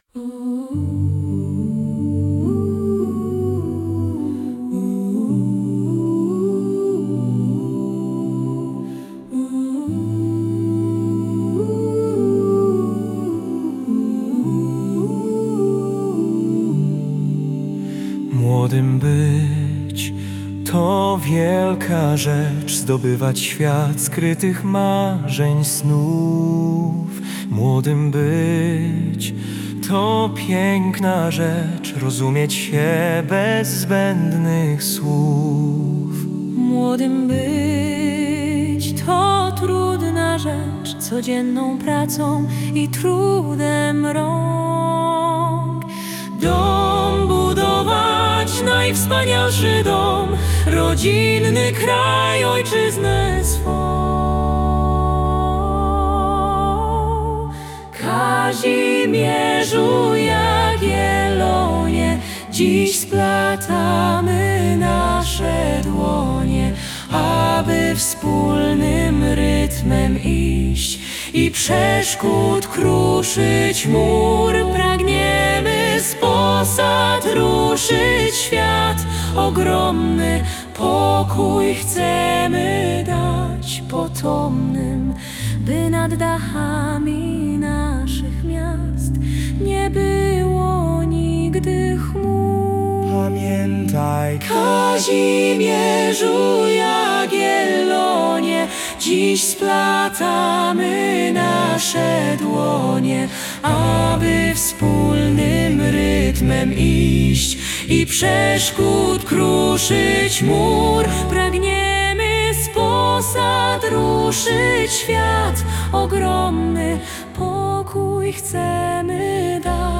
03-hymn-ii-lo-chor-gospel-acapella.mp3